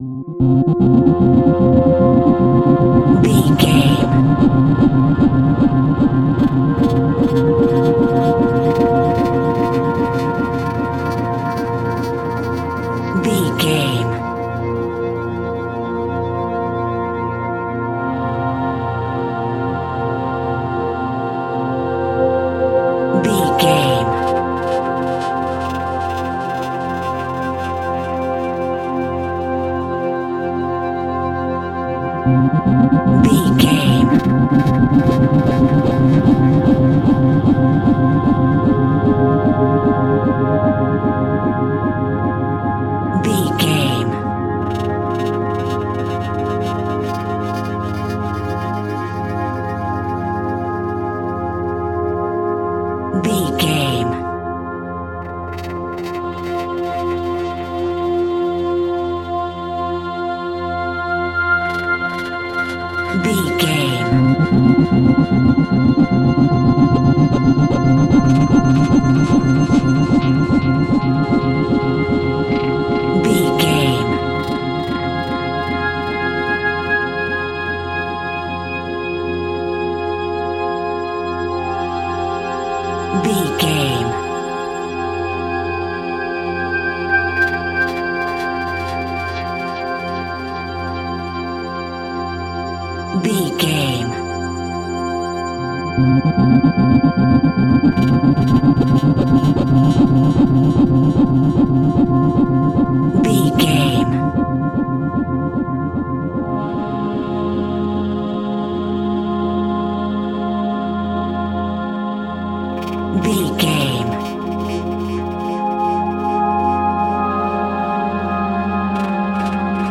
Aeolian/Minor
ominous
dark
eerie
synthesiser
ambience
pads